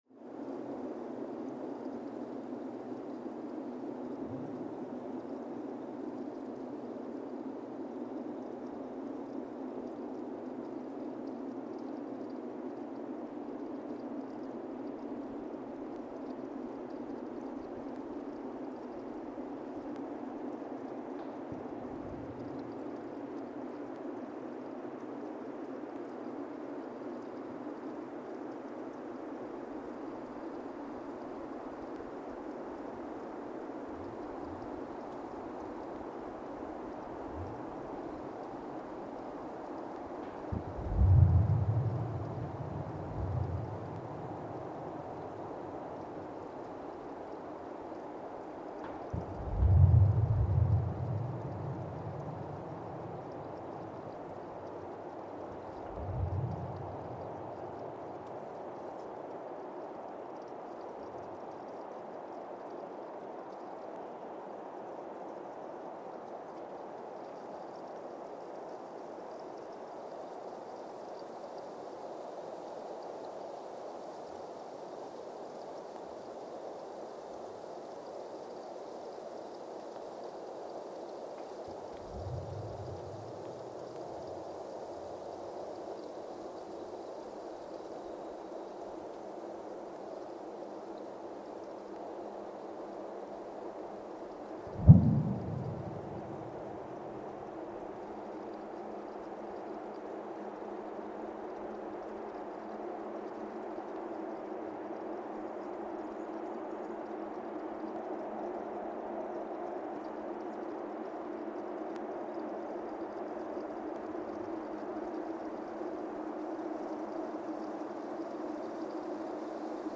Weston, MA, USA (seismic) archived on December 14, 2024
Sensor : CMG-40T broadband seismometer
Speedup : ×1,800 (transposed up about 11 octaves)
Loop duration (audio) : 05:36 (stereo)
Gain correction : 25dB
SoX post-processing : highpass -2 90 equalizer 300 2q -6 equalizer 400 2q -6 equalizer 90 12q 6